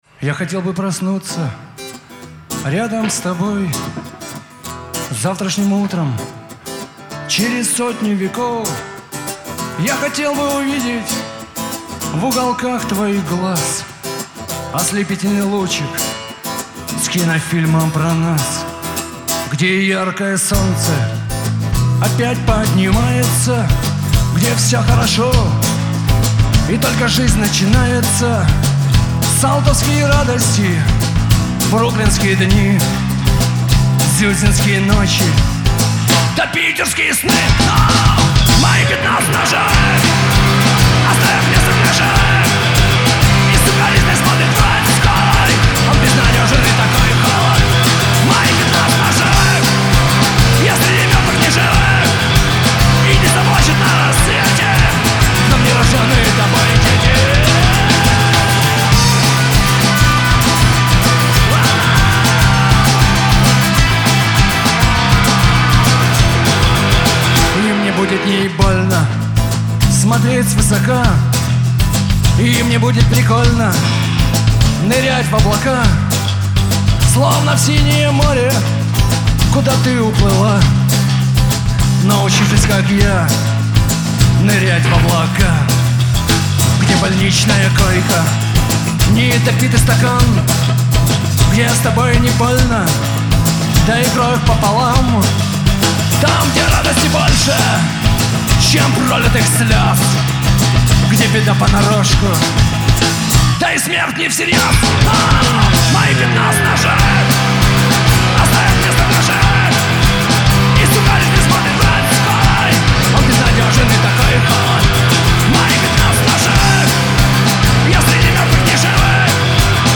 вокал, ак. гитара